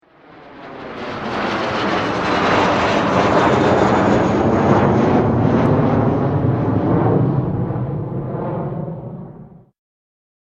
小型ジェット（164KB）